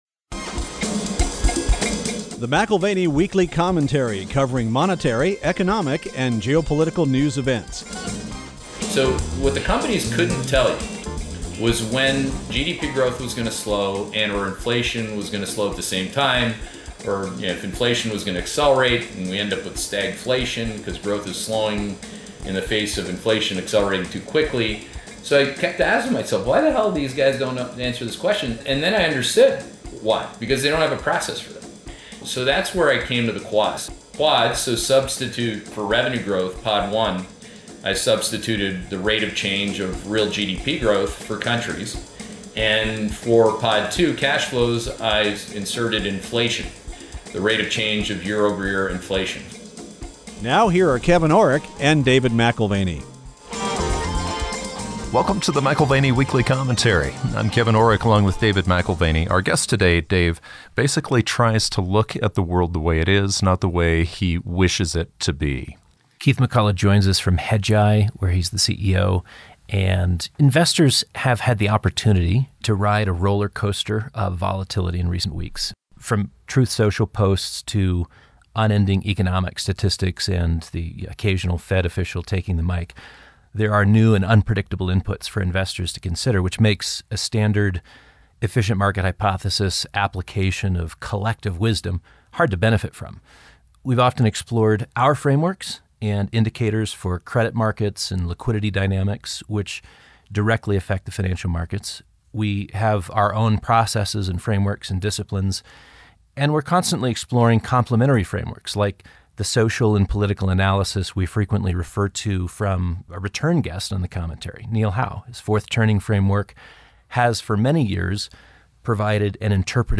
In this conversation, we discuss: